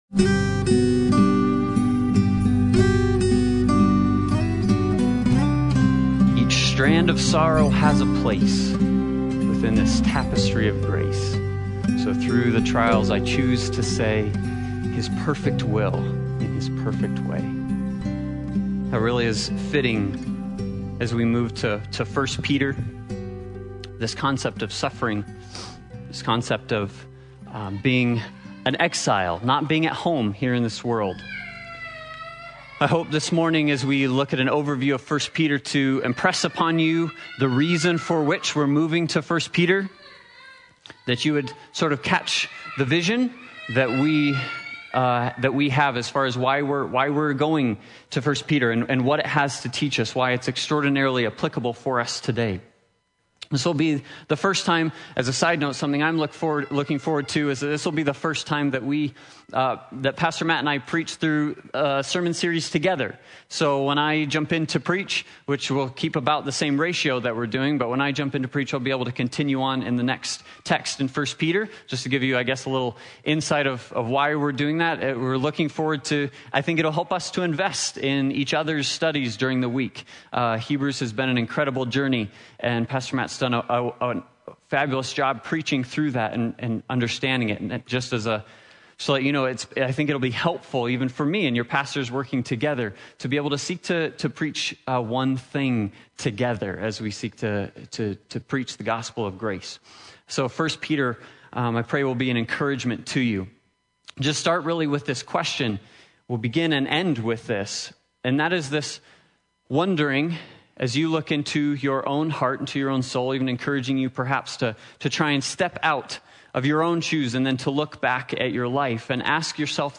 1 Peter Service Type: Sunday Morning Worship « Stick with Jesus